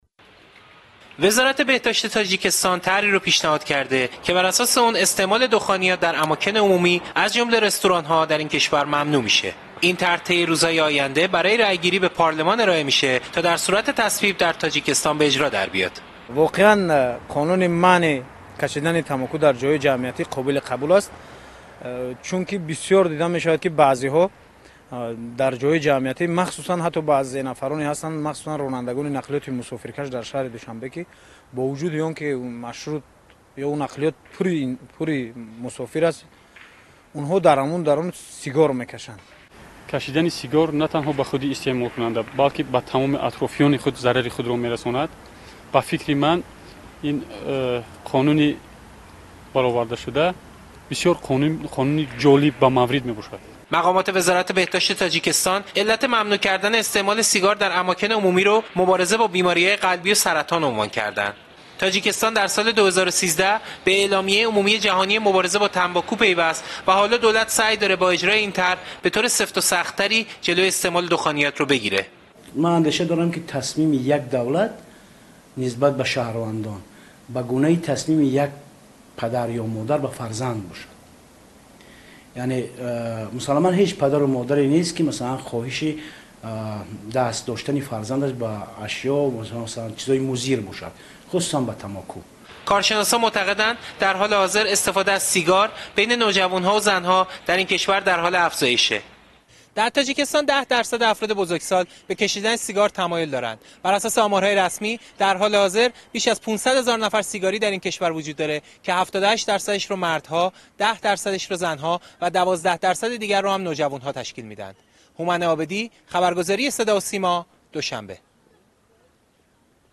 аз Душанбе гузориш медиҳад